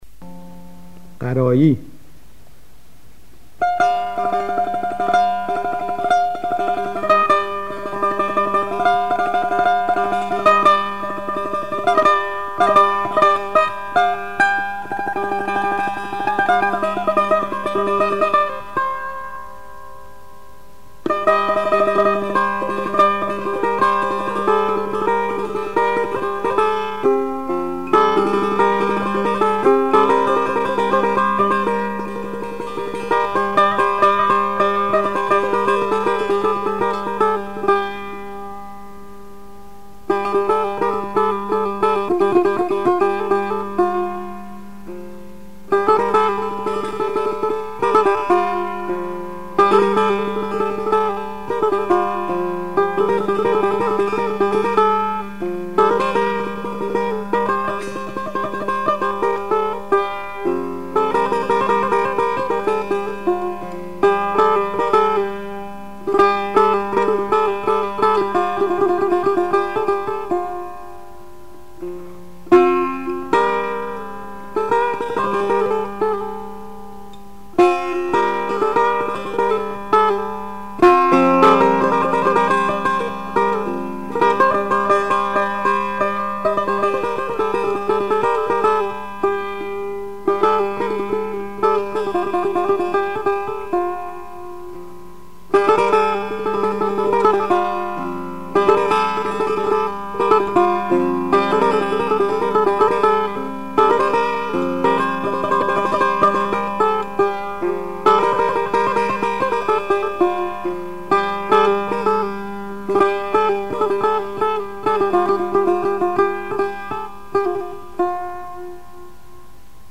آواز افشاری ردیف میرزا عبدالله سه تار
استاد طلایی در اجرای خود با ساز سه تار، به خوبی توانسته است همانندی‌های این آواز را با دستگاه‌های نوا و ماهور به نمایش گذارد.